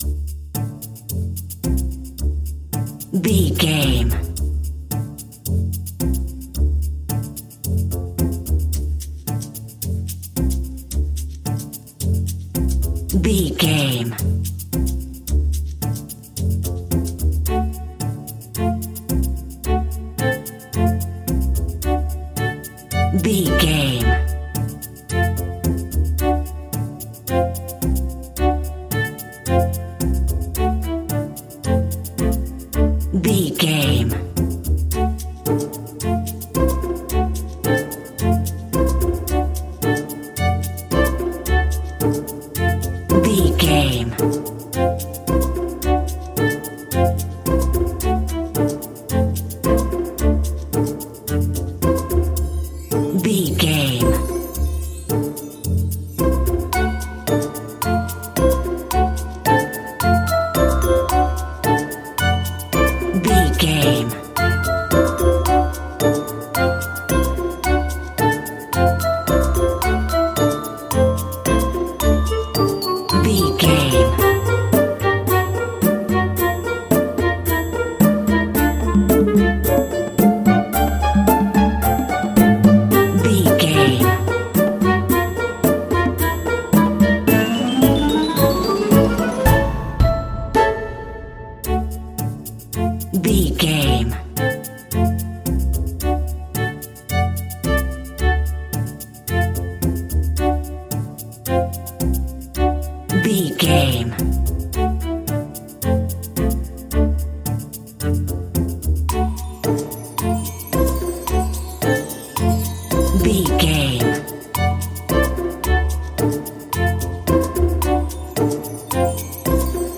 Ionian/Major
D
orchestra
flutes
percussion
conga
oboe
strings
silly
cheerful
quirky